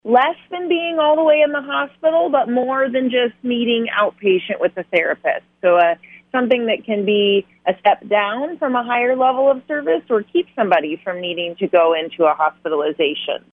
State Senator from Ames, Kara Warme, spoke on KFJB yesterday before the vote to explains what subacute care is.